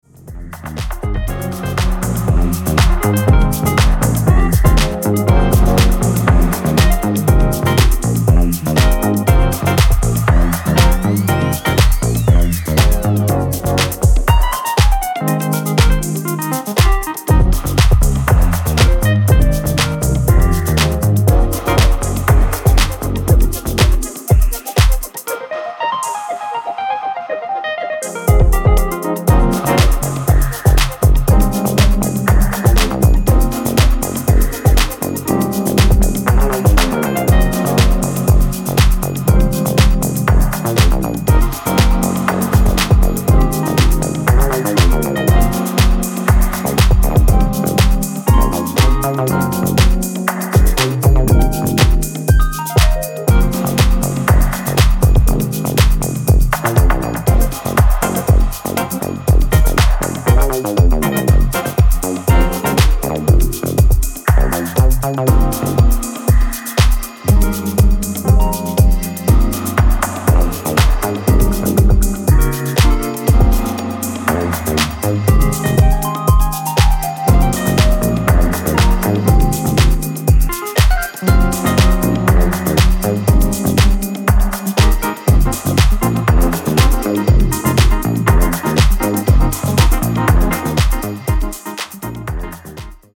ここでは、自身の手による鍵盤やフルートなどのジャジーな演奏を用いながら温かくしなやかなフュージョン・ハウスを展開。